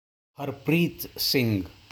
Harpreet Singh (Huhr-preet Singh)